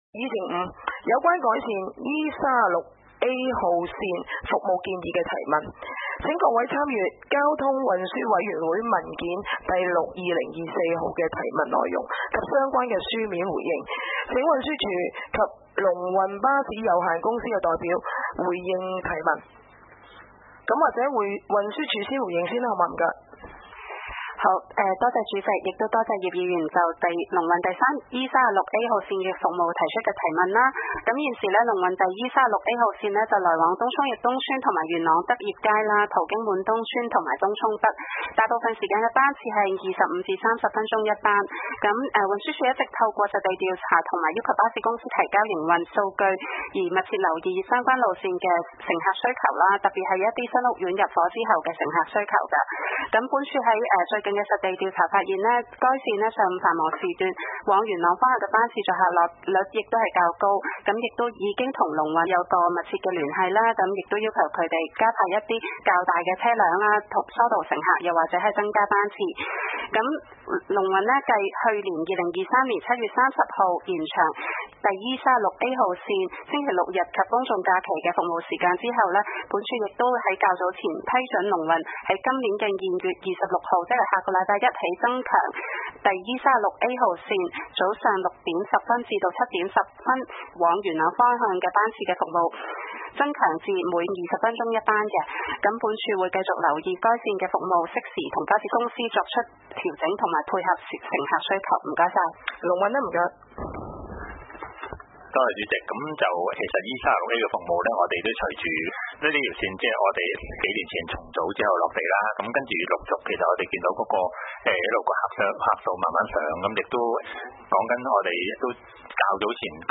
離島區議會 - 委員會會議的錄音記錄
會議的錄音記錄